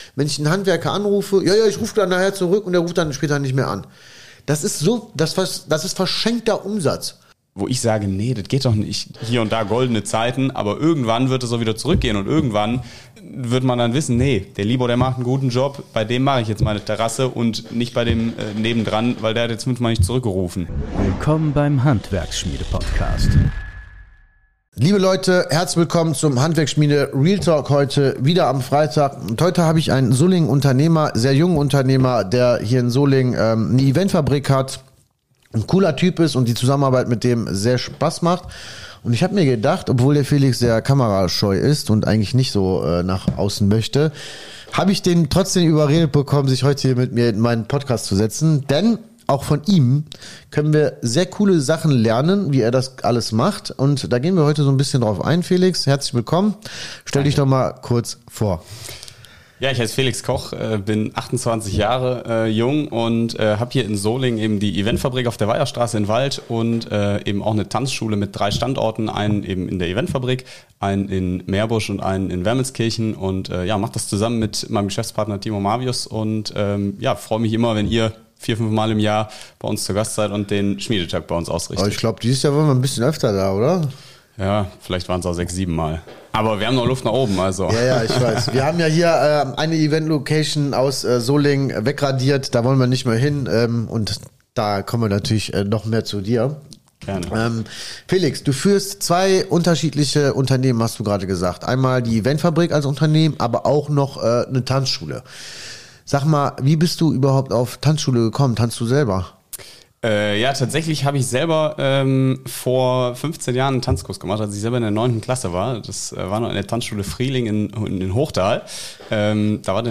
Warum Dienstleistung im Handwerk scheitert | Interview